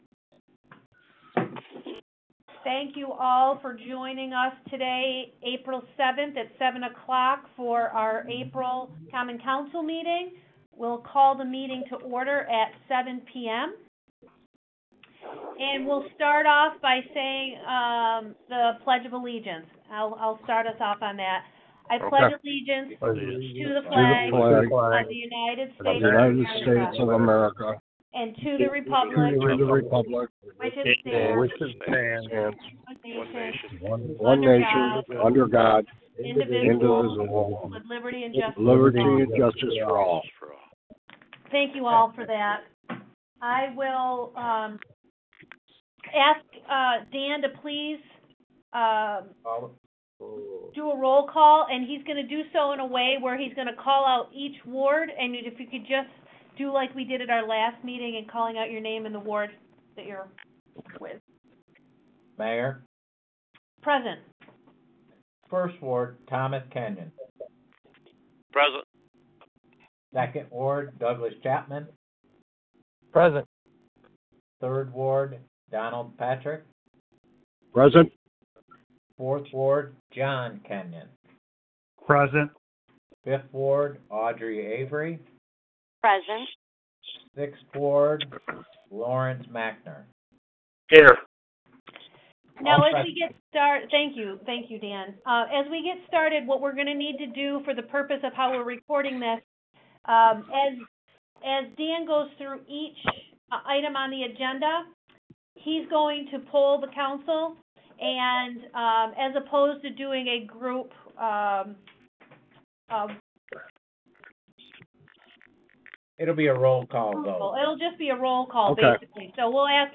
The Fulton Common Council held its April meeting via teleconference today April 7 at 7 p.m., in which they voted on and approved ten resolutions.
Since the meeting did not take place in person, there was no public comment available.